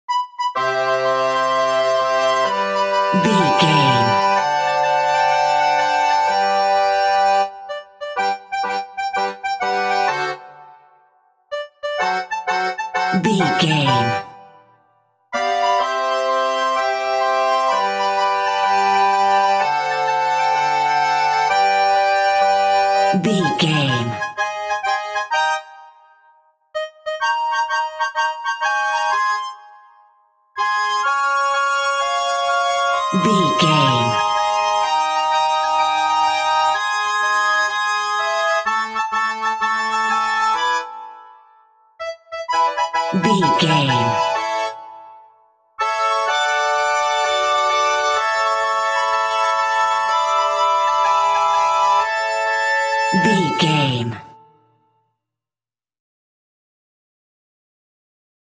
Uplifting
Ionian/Major
childrens music
Pizz Strings